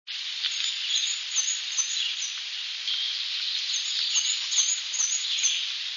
Given its very high and faint song, it is easiest to observe in late April combing branches for insects just before the leaves have unfolded and prior to the arrival of other much louder birds.